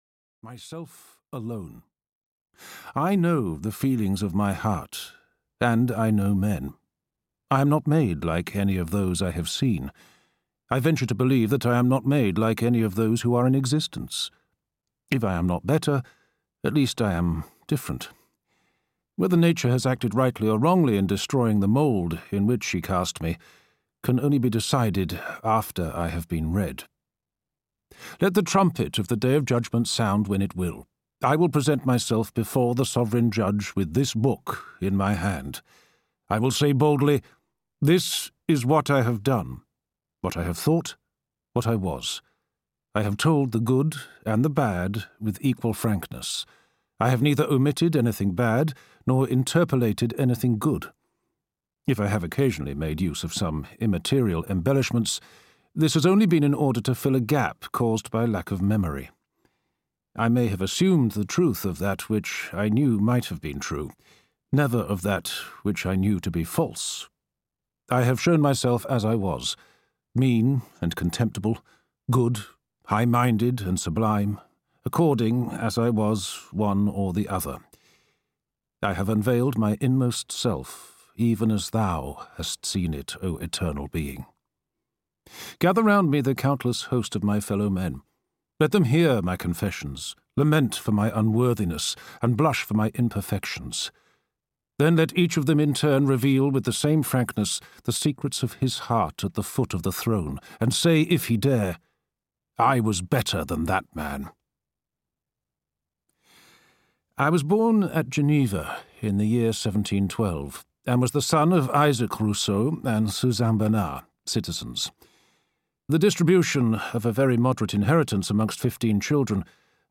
Audio knihaThe Confessions of Jean-Jacques Rousseau (EN)
Ukázka z knihy